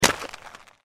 sporecarrier_foot_r03.mp3